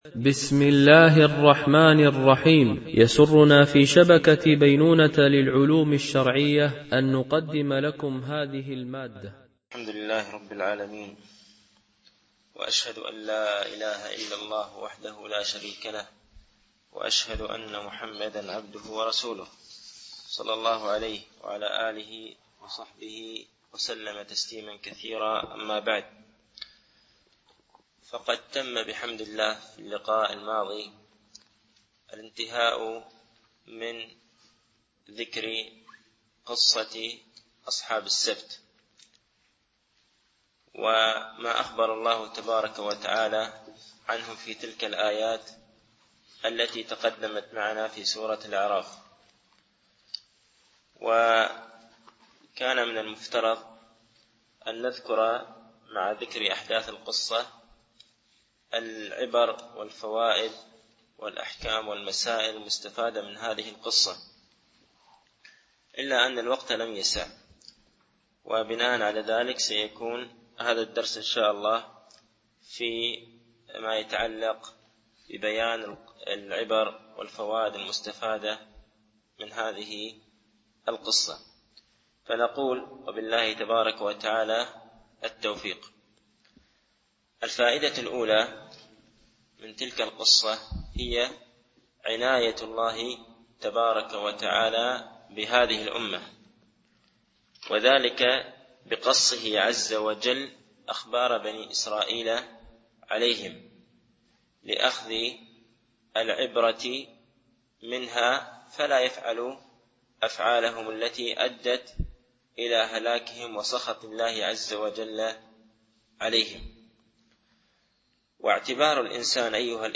شرح أعلام السنة المنشورة ـ الدرس 167( تكملة سؤال - على من يجب الأمر بالمعروف والنهي عن المنكر وما مراتبه ؟)
الألبوم: شبكة بينونة للعلوم الشرعية التتبع: 167 المدة: 62:39 دقائق (14.38 م.بايت) التنسيق: MP3 Mono 22kHz 32Kbps (CBR)